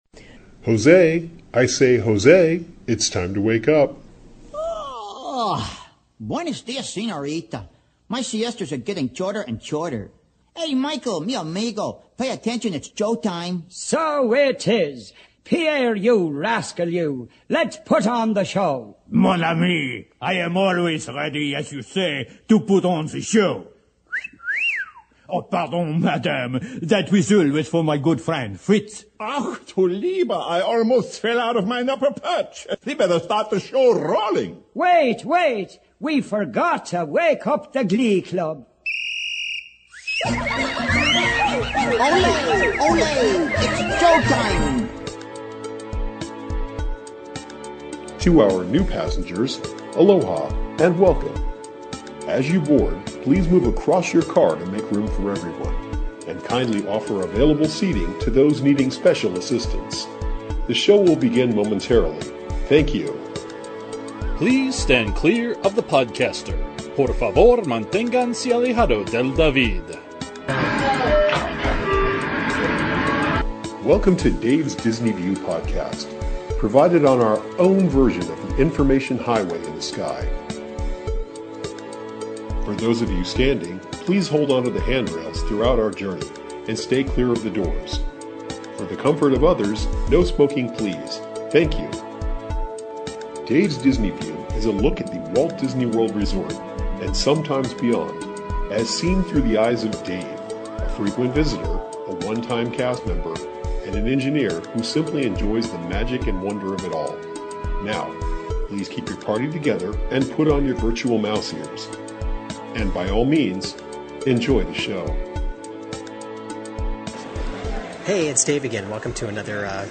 Continuing the trip in March, here's some audio from - and some of my thoughts about - World Shocase in EPCOT